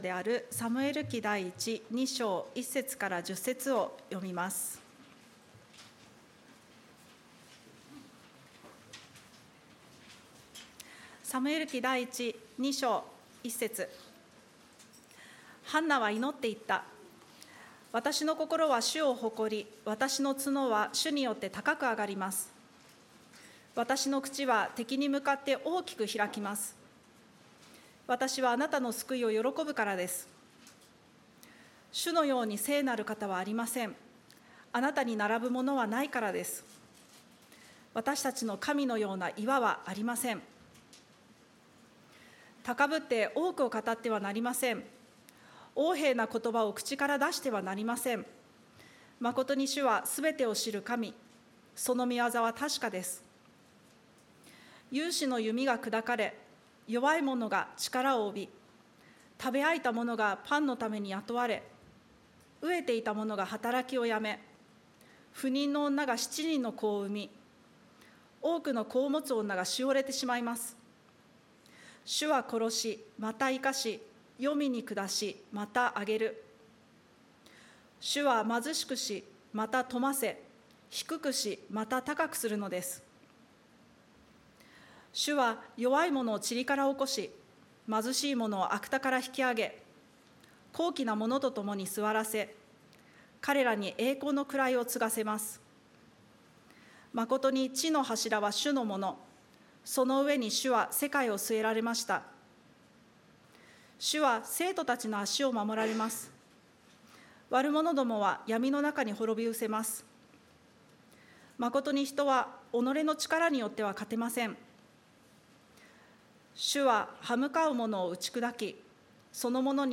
礼拝メッセージ(説教)